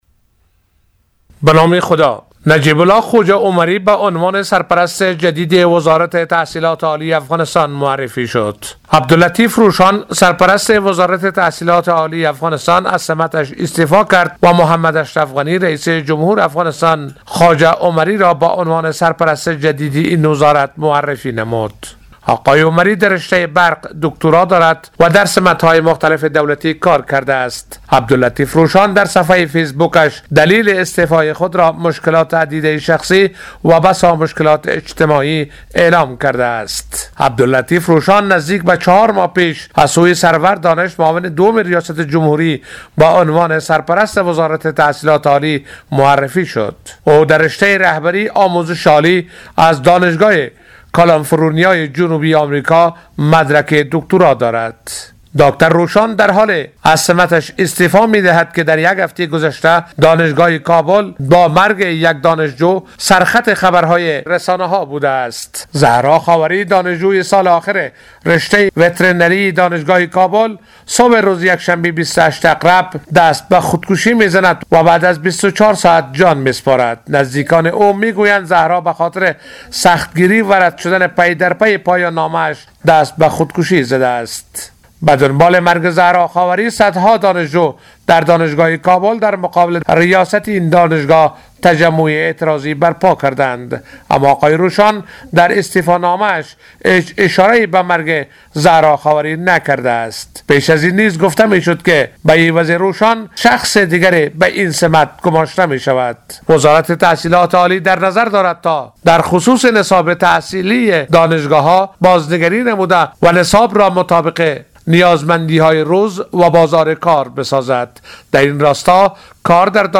گزارش همکارمان